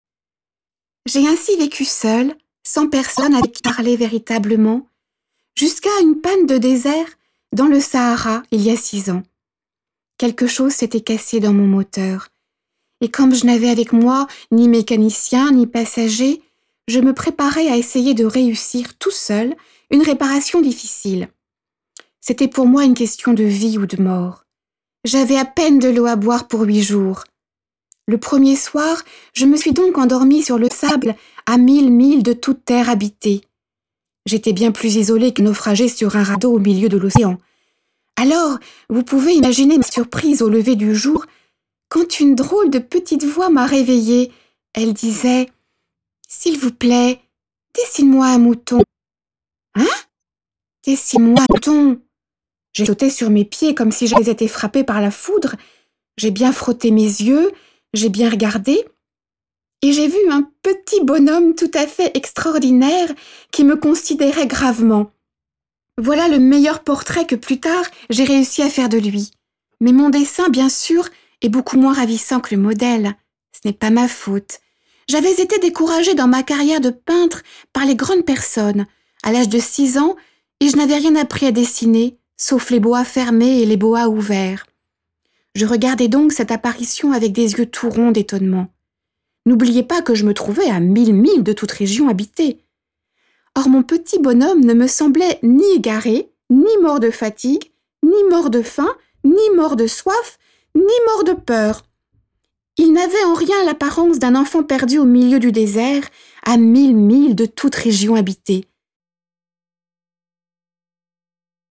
Voix Pub : La boite à musique